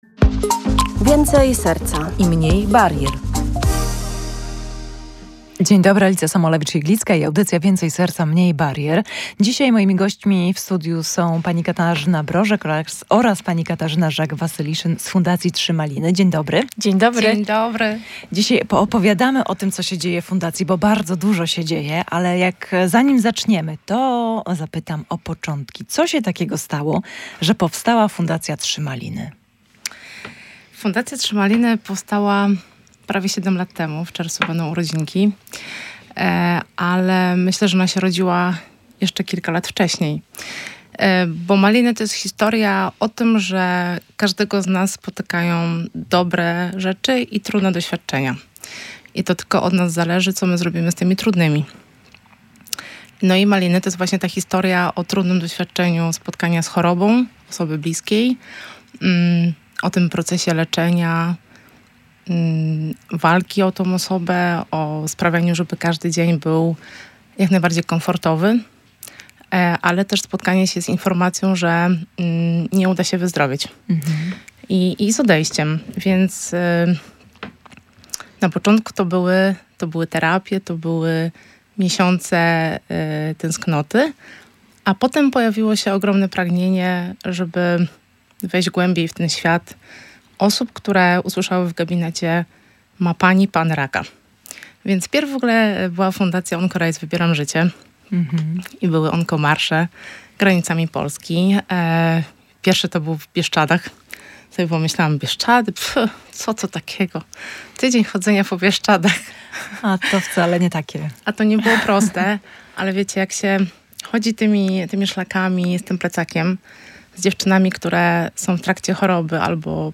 O tym z gośćmi rozmawiała